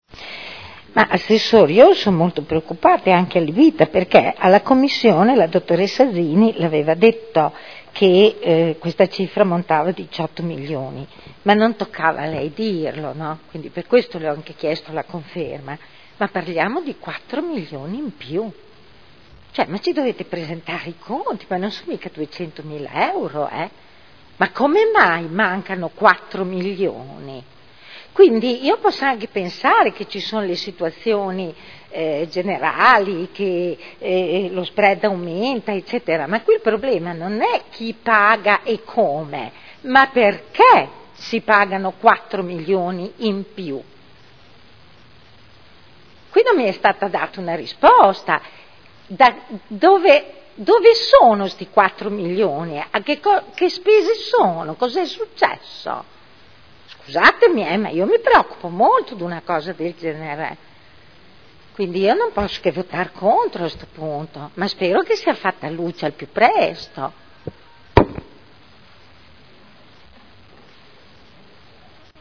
Seduta del 20/02/2012 Dichiarazione di voto.